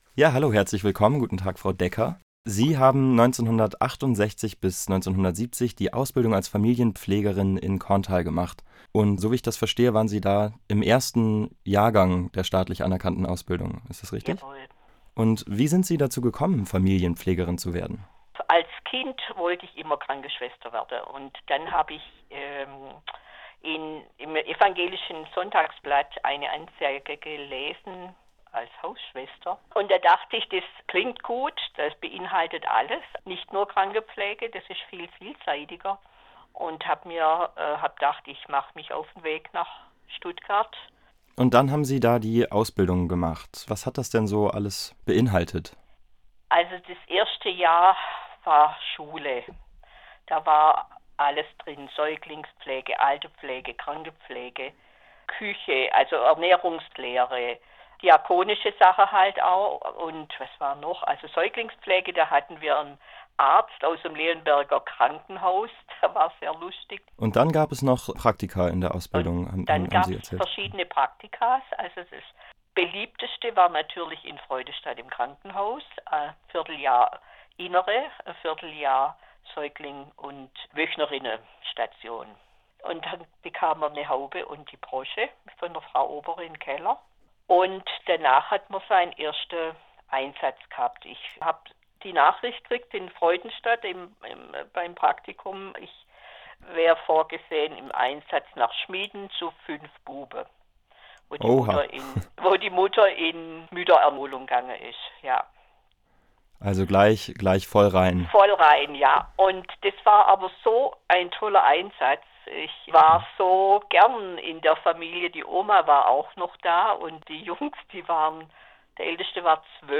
Außerdem finden sich hier Interviews mit ehemaligen Schwestern und heute tätigen Familienpfleger:innen, die von ihren persönlichen Erfahrungen während der Ausbildung und in ihren Einsätzen berichten.